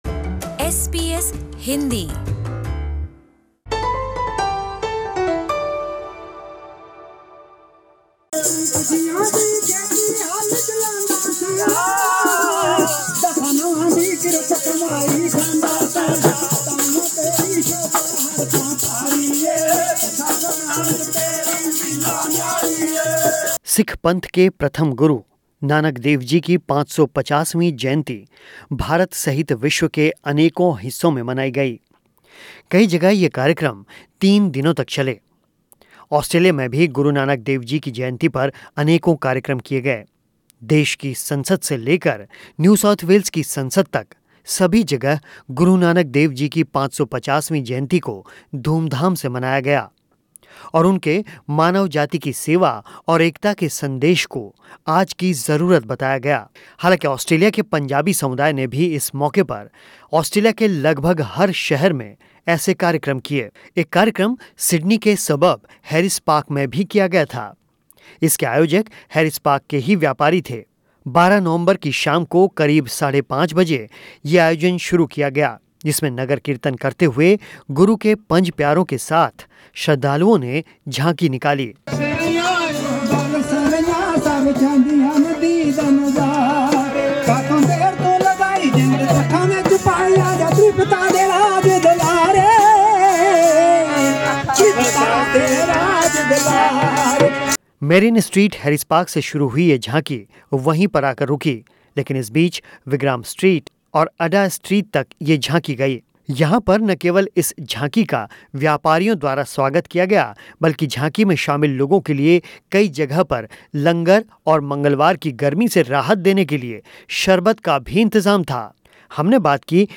ज़ाहिर ये लोग कीर्तन करते हुए आगे बढ़ रहे थे.
एसबीएस हिंदी से बातचीत में उन्होंने सिख समुदाय को इस मौके पर शुभकामनाएं दीं.